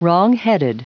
Prononciation du mot wrongheaded en anglais (fichier audio)
Prononciation du mot : wrongheaded